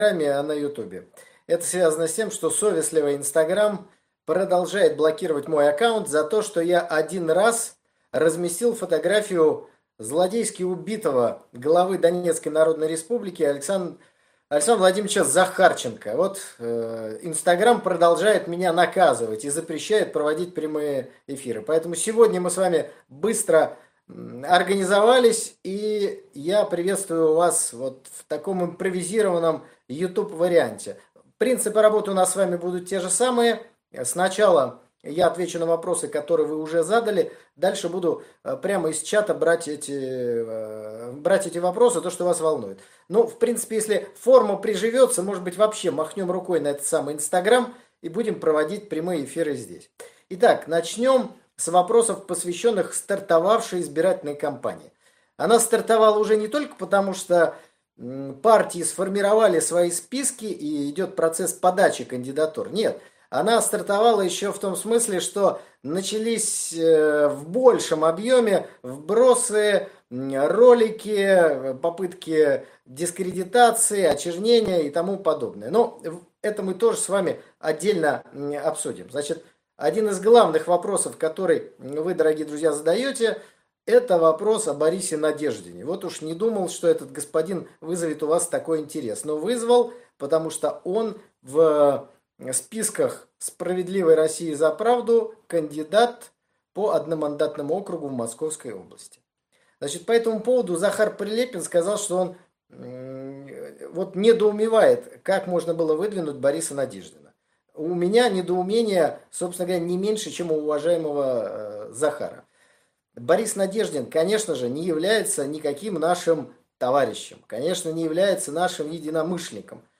Николай Стариков. Прямой эфир. Ответы на вопросы